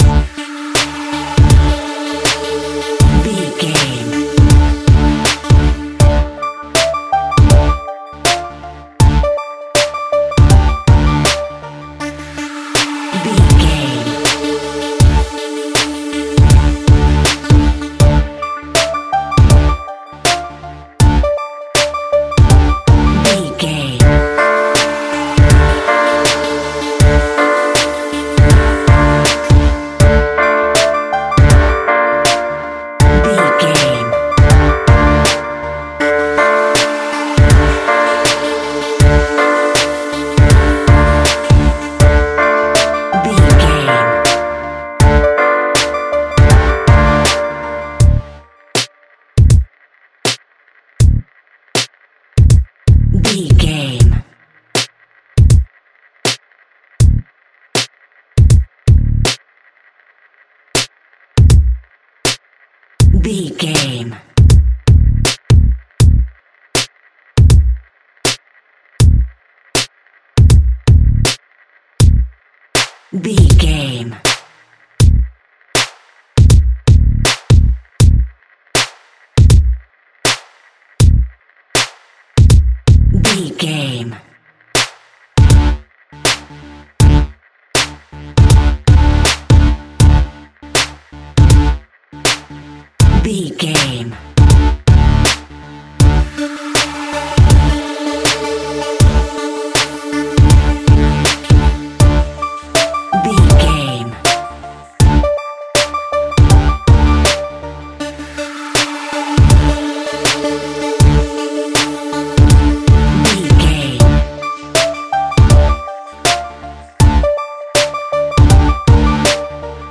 Aeolian/Minor
D
hip hop
hip hop instrumentals
funky
groovy
east coast hip hop
electronic drums
synth lead
synth bass